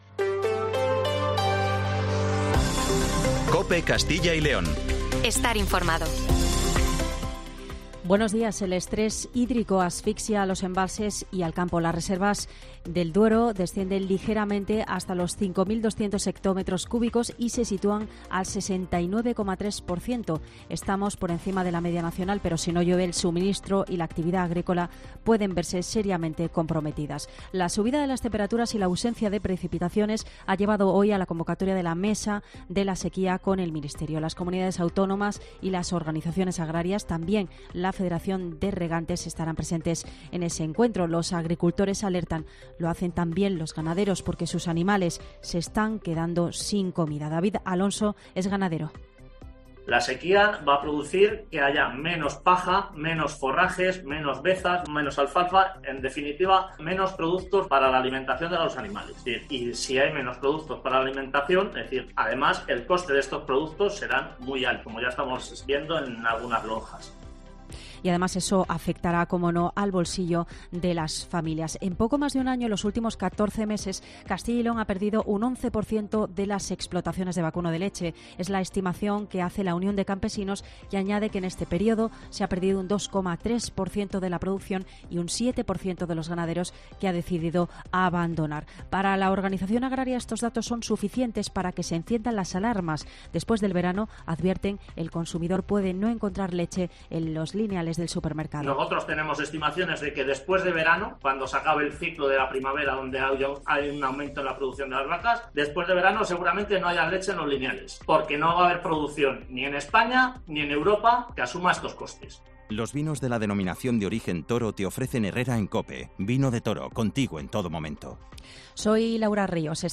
Informativo Matinal 7:50